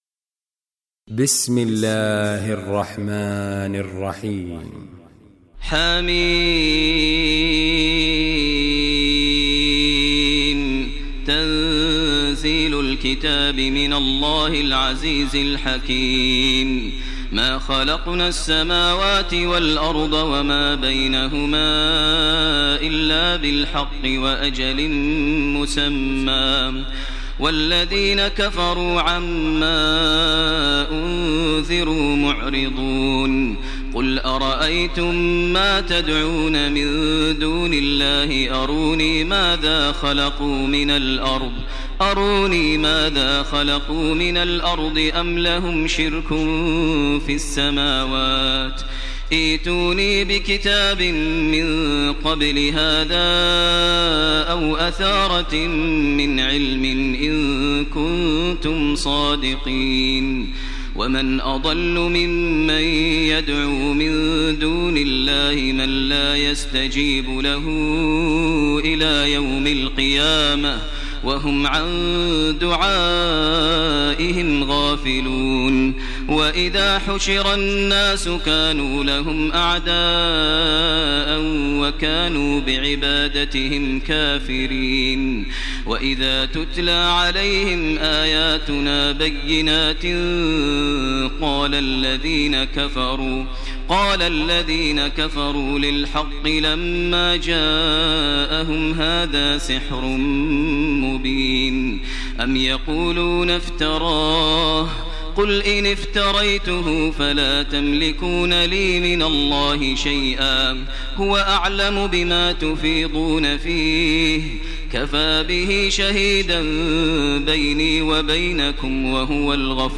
Surah Ahqaf Recitation by Sheikh Maher al Mueaqly
Surah Ahqaf, listen online mp3 tilawat / recitation in the voice of Imam e Kaaba Sheikh Maher al Mueaqly.